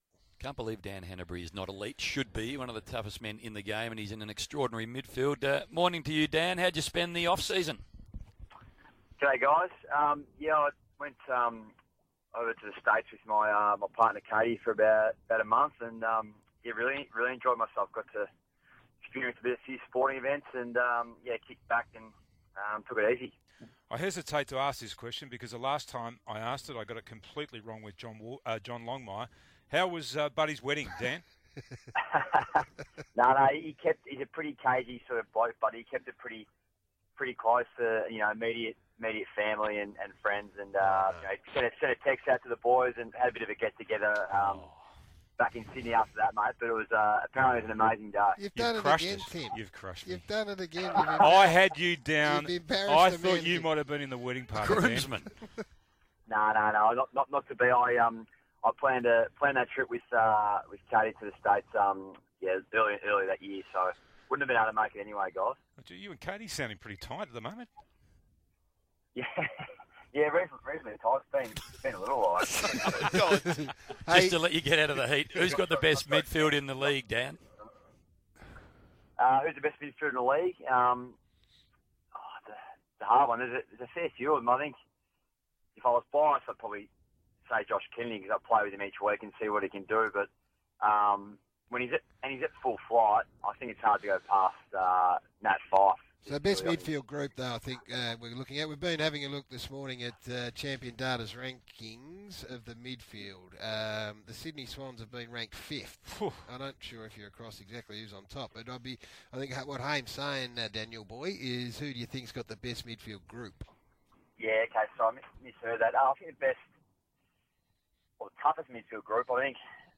Star midfielder Dan Hannebery joins Thursday's edition of SEN Breakfast with Garry Lyon, Tim Watson and Hamish McLachlan.